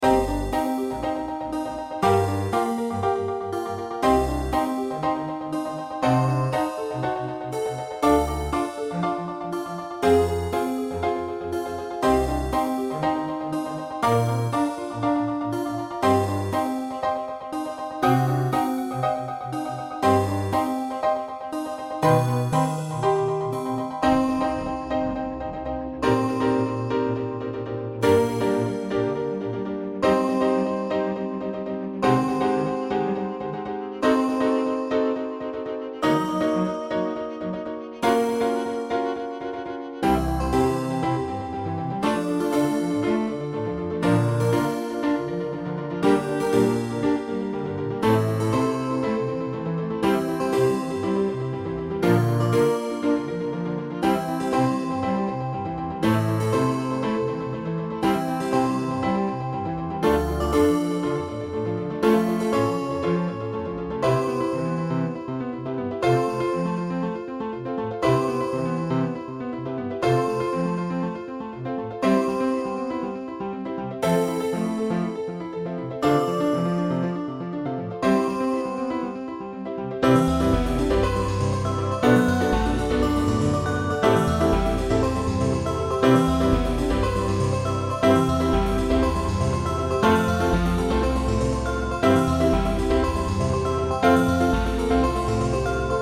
BGM
Fx5(ブライトネス)、ピアノ、コントラバス